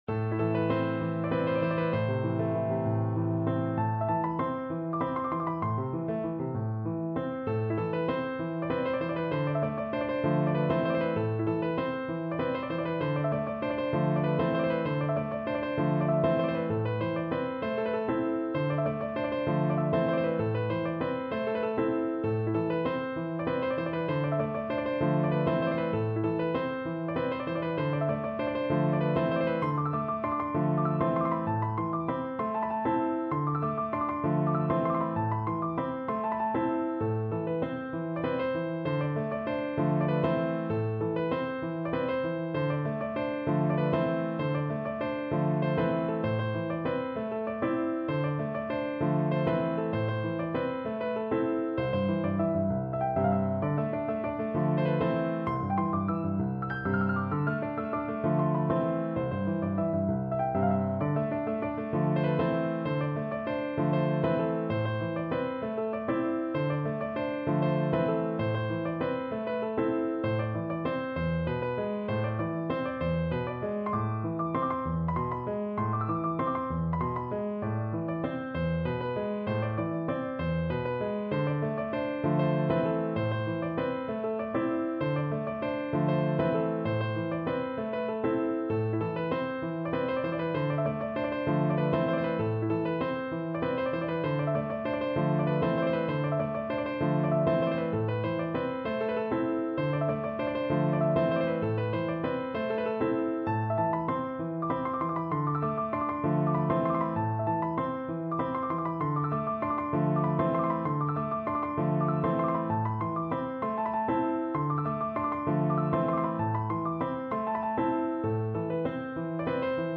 Müəllif: Azərbaycan Xalq Rəqsi